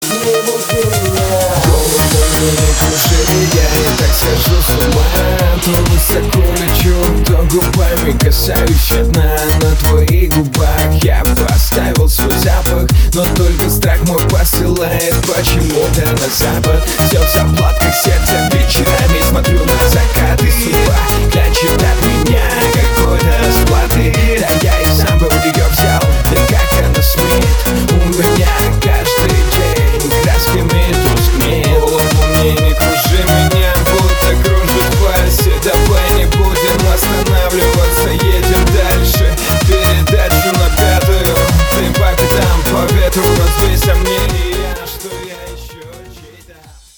• Качество: 320, Stereo
громкие
dance
EDM
electro house